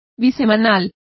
Complete with pronunciation of the translation of biweekly.